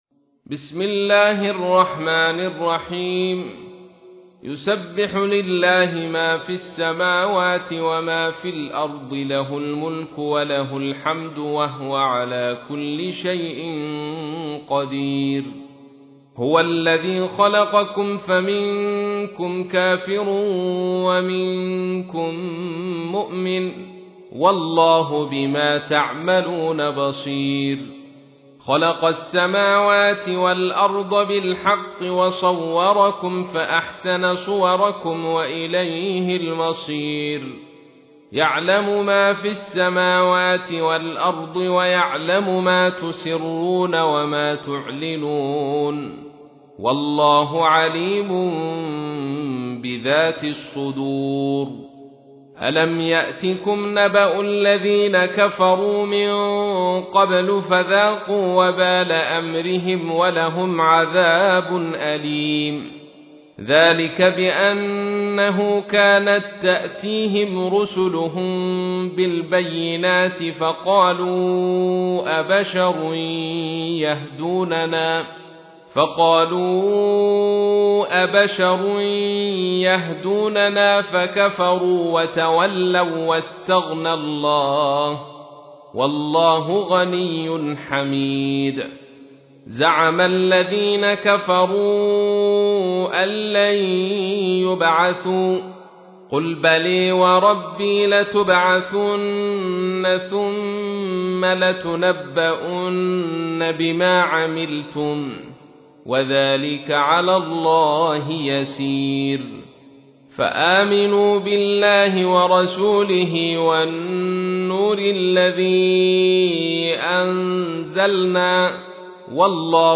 تحميل : 64. سورة التغابن / القارئ عبد الرشيد صوفي / القرآن الكريم / موقع يا حسين